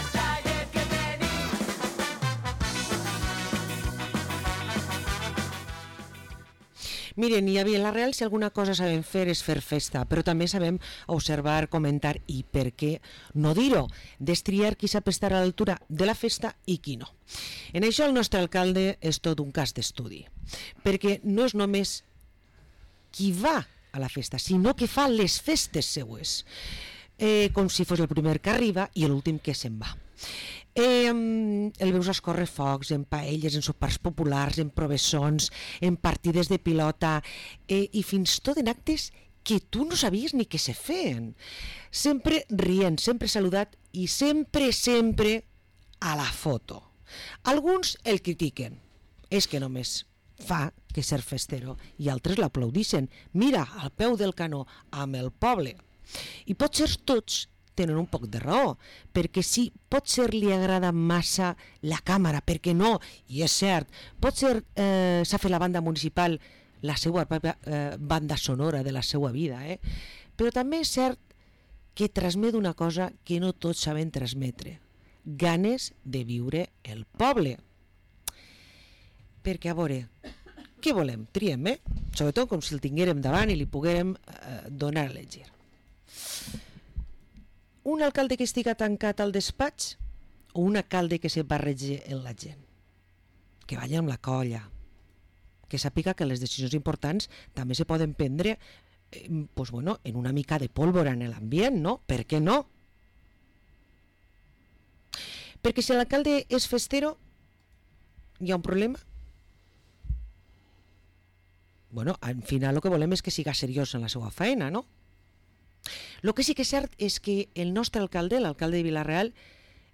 Parlem amb José Benlloch, alcalde de Vila-real